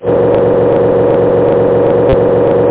car_run.mp3